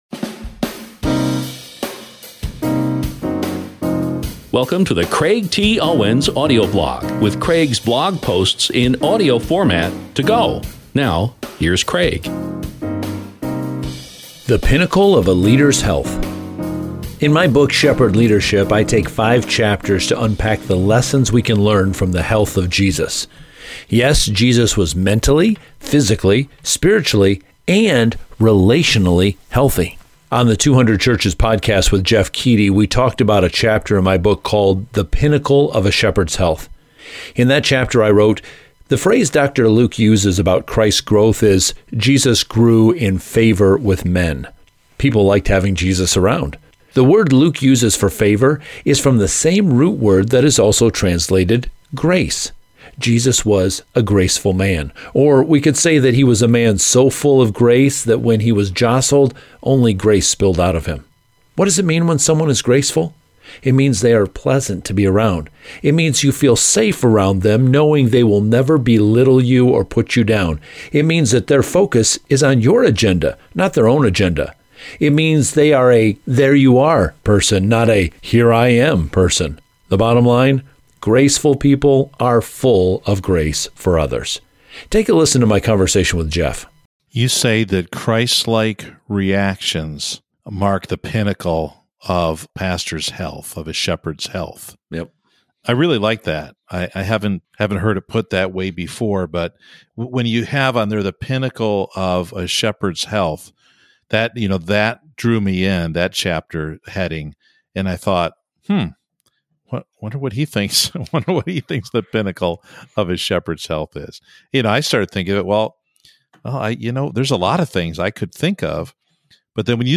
If you want to catch up on some of the other clips I’ve already shared from this interview, you can find them here, and I’ll be sharing more clips from this 200churches interview soon, so please stay tuned.